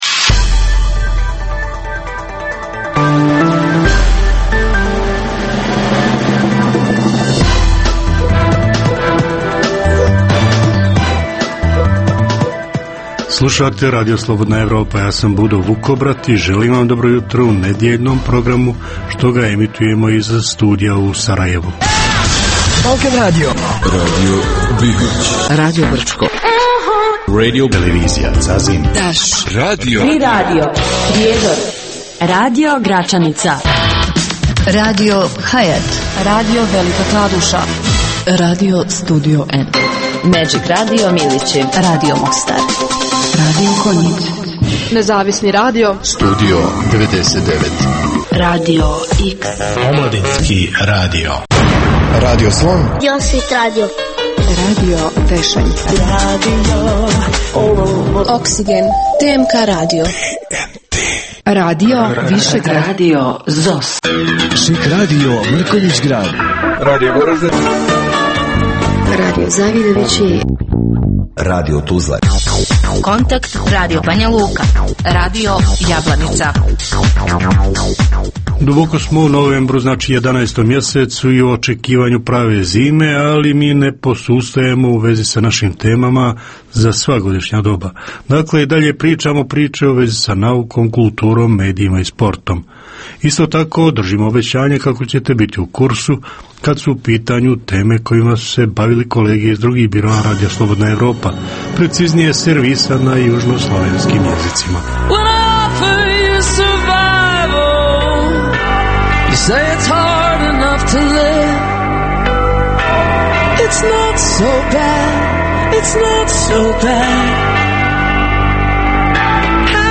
Jutarnji program namijenjen slušaocima u Bosni i Hercegovini. Sadrži novosti iz svijeta nauke, medicine, visokih tehnologija, sporta, filma i muzike.